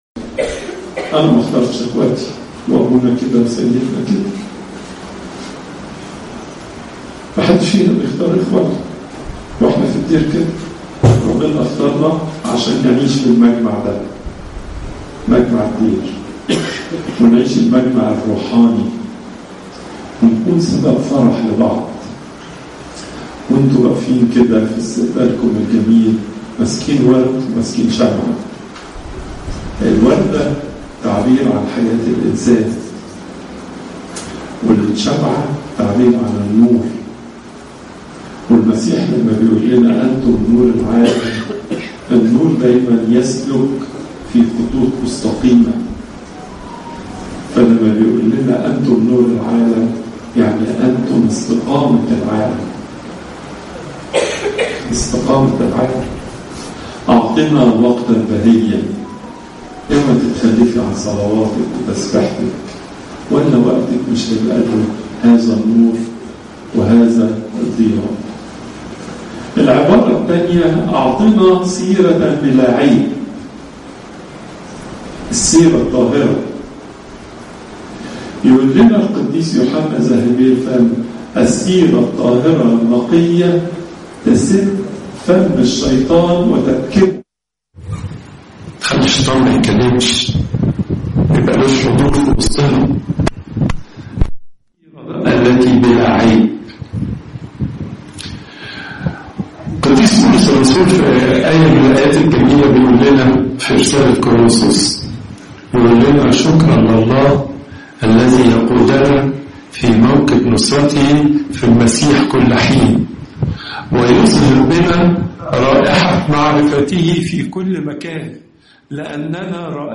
Popup Player Download Audio Pope Twadros II Tuesday, 30 September 2025 12:44 Pope Tawdroes II Weekly Lecture Hits: 90